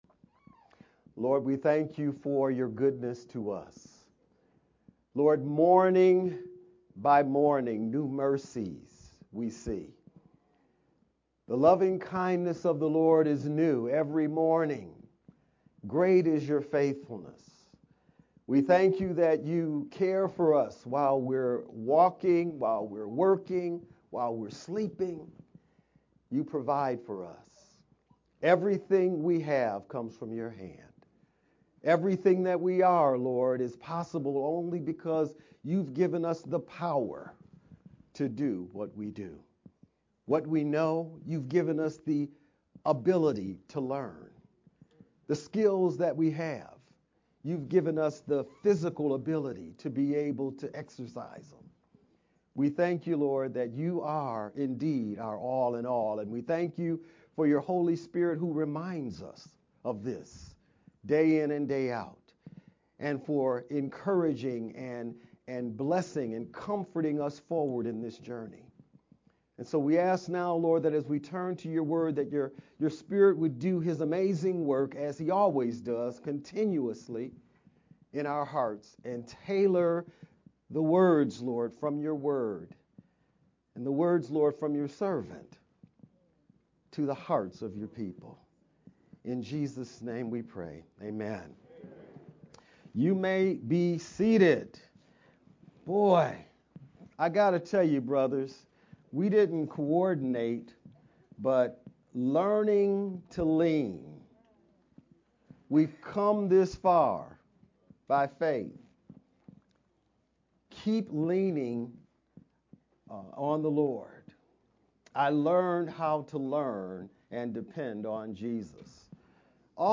Feb-2nd-VBCC-Sermon-only-edited_Converted-CD.mp3